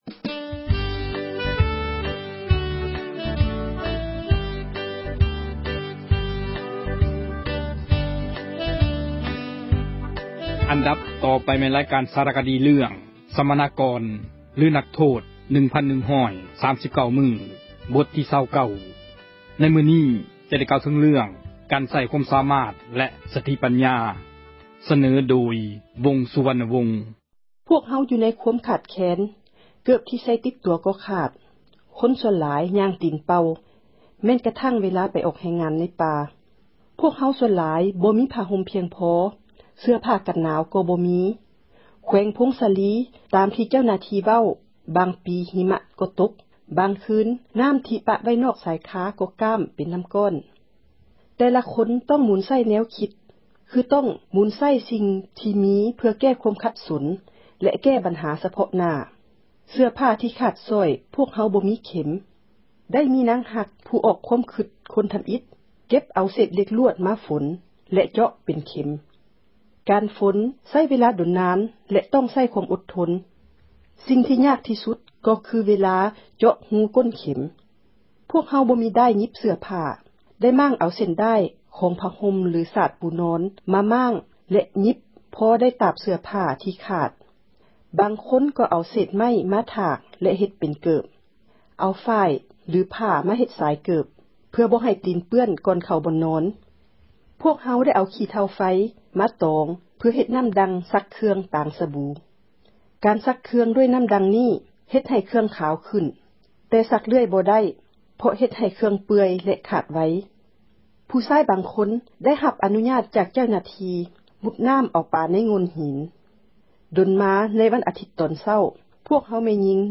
ຣາຍການ ສາຣະຄະດີ ເຣື້ອງ “ສັມມະນາກອນ ຫຼື ນັກໂທດ 1,139 ມື້” ບົດທີ່ 29. ໃນມື້ນີ້ ຈະໄດ້ ກ່າວເຖີງ ເຣື້ອງ ການໃຊ້ ຄວາມສາມາດ ແລະ ສະຕິ ປັນຍາ.